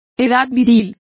Complete with pronunciation of the translation of manhood.